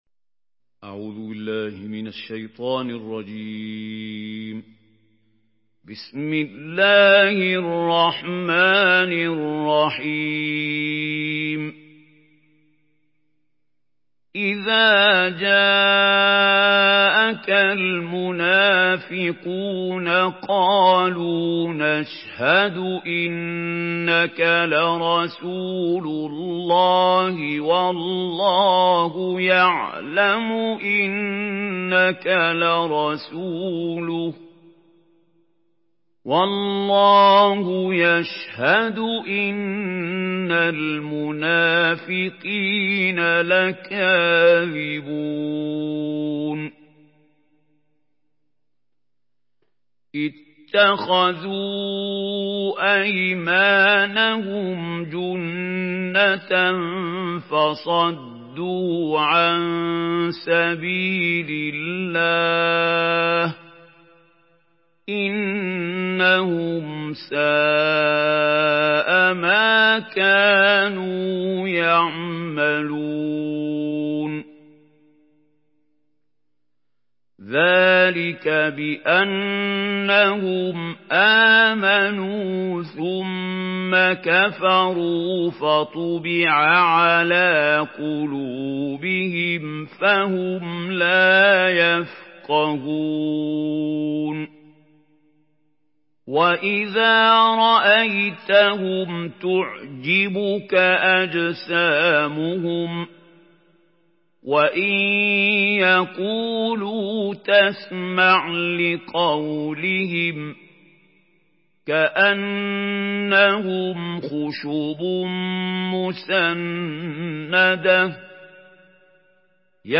Sourate Al-Munafiqun MP3 à la voix de Mahmoud Khalil Al-Hussary par la narration Hafs
Une récitation touchante et belle des versets coraniques par la narration Hafs An Asim.
Murattal Hafs An Asim